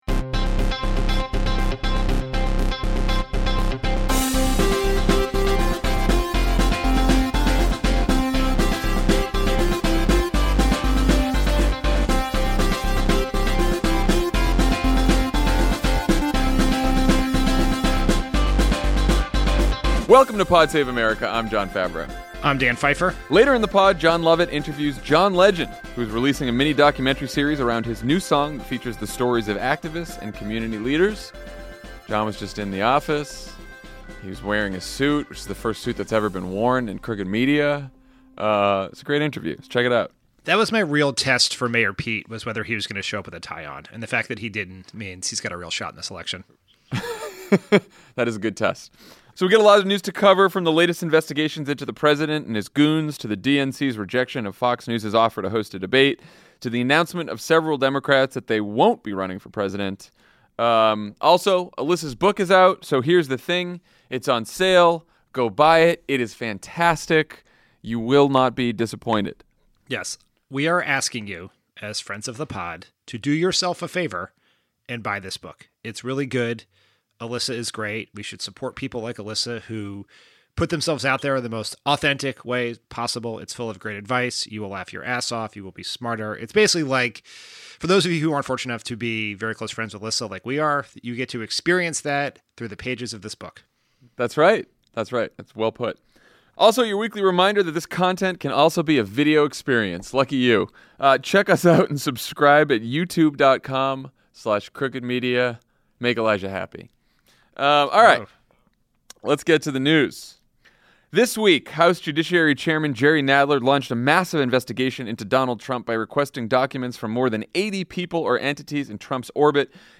Congress launches investigations into Donald Trump that could lay the groundwork for impeachment, Democrats decide against allowing Fox News to host a primary debate, and five potential Democratic contenders decide against running for president in 2020. Then John Legend joins Jon Lovett in studio to talk about his new song and mini-documentary series that features the stories of activists and community leaders.